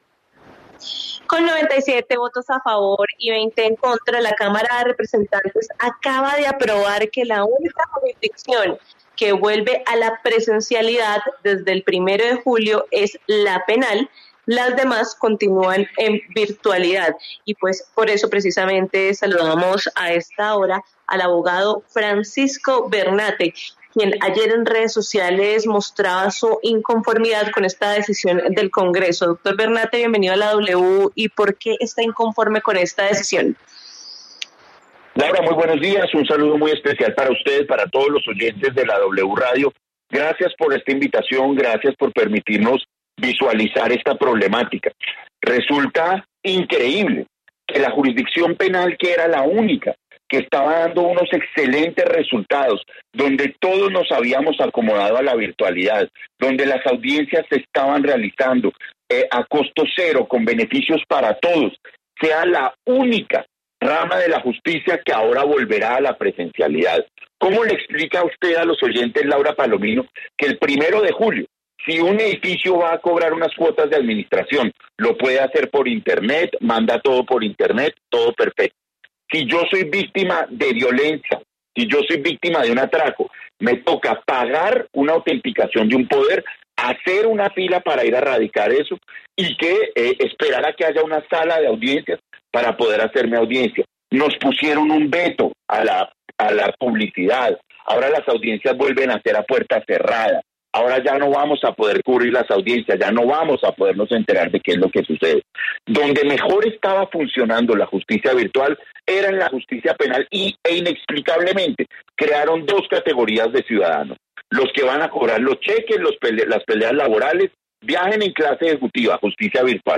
El congresista Jaime Rodríguez respondió.